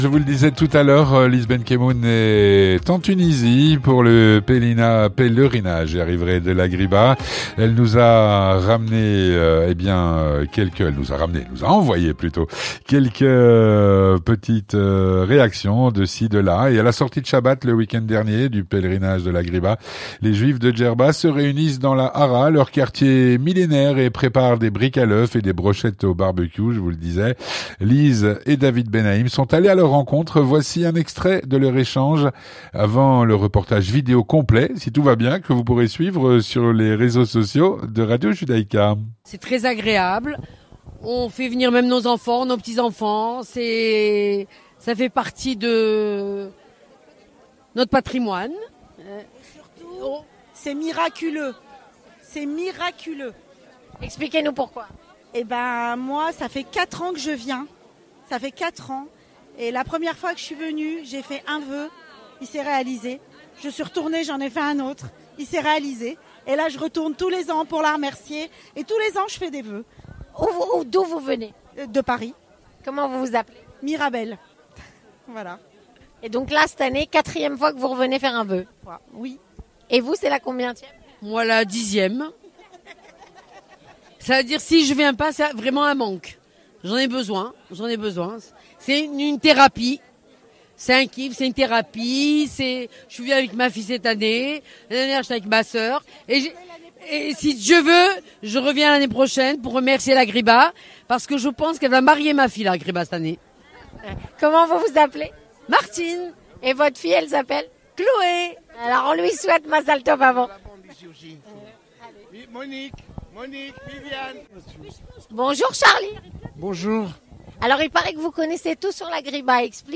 Vue du Maghreb - Rencontre avec des juifs de Djerba pendant le pèlerinnage de la Ghriba